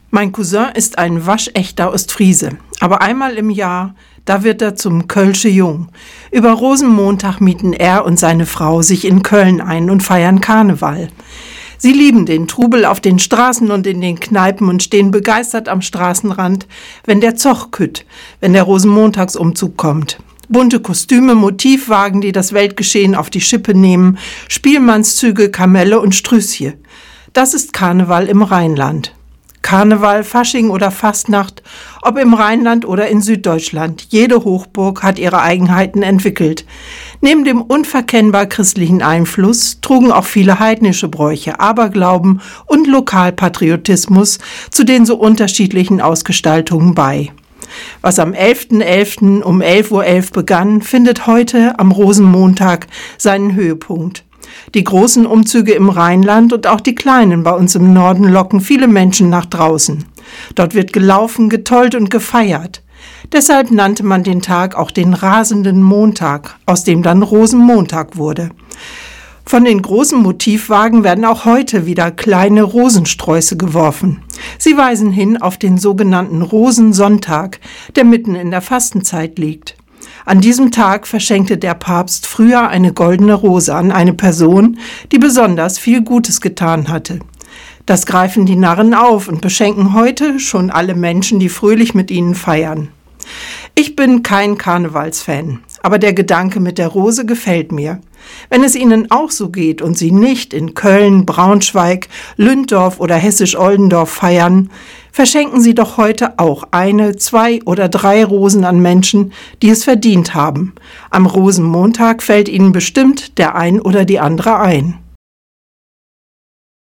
Radioandacht vom 3. März